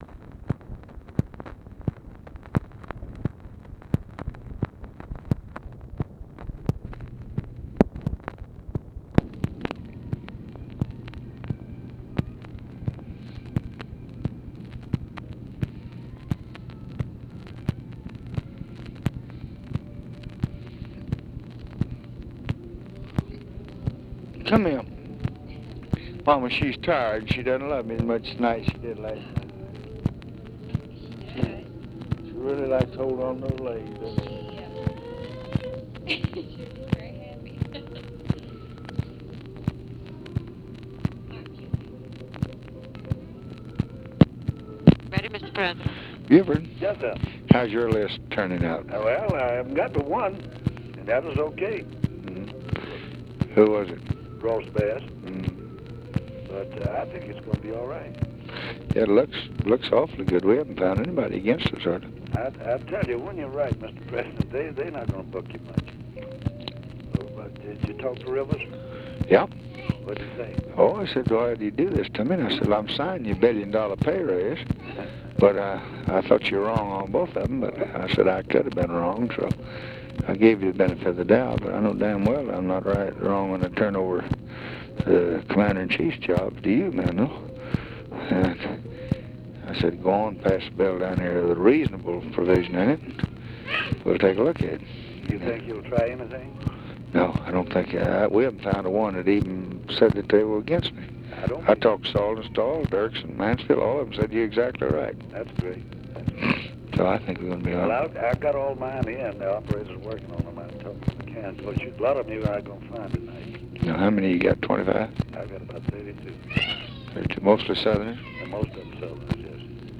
Conversation with BUFORD ELLINGTON and OFFICE CONVERSATION, August 21, 1965
Secret White House Tapes